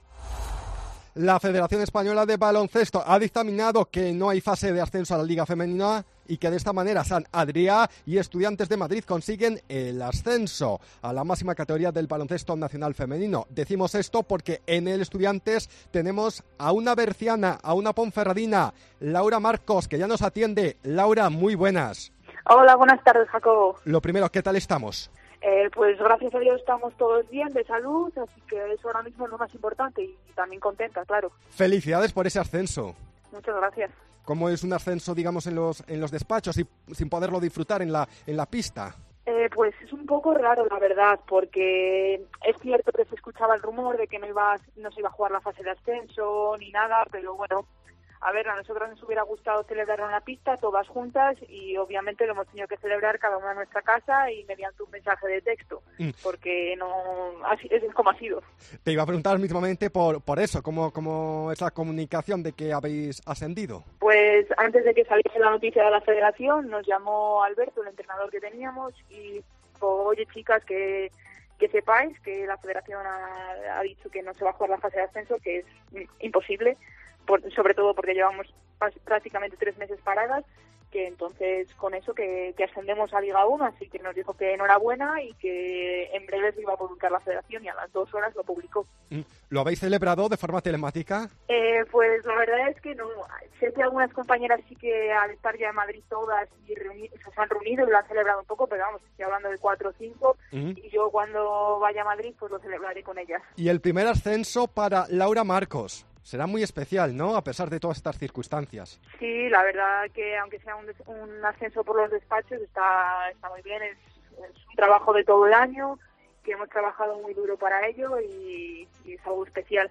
AUDIO: Escucha aquí la entrevista que le hemos realizado en Deportes COPE Bierzon